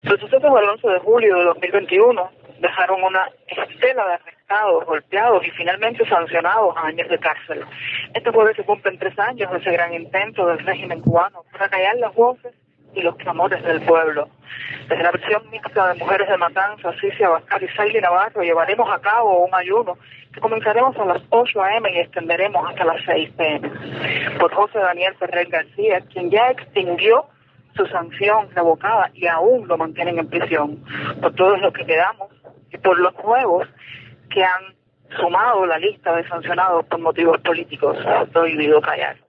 Las opositoras encarceladas en la prisión La Bellotex, en la provincia de Matanzas, hicieron publica su decisión a través de un mensaje de audio enviado a Martí Noticias.